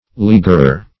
leaguerer - definition of leaguerer - synonyms, pronunciation, spelling from Free Dictionary Search Result for " leaguerer" : The Collaborative International Dictionary of English v.0.48: Leaguerer \Lea"guer*er\, n. A besieger.